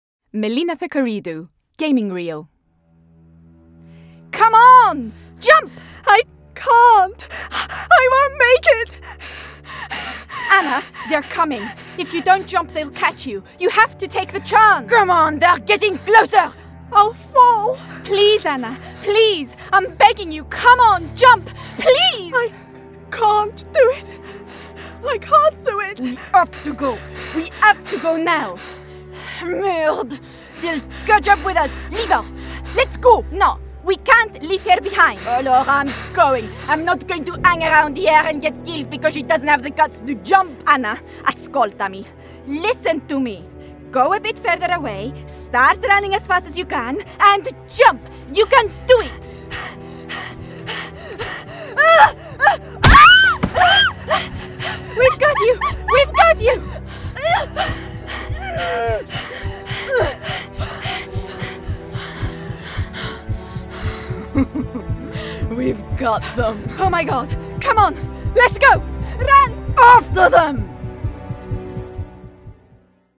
Video Games Reel: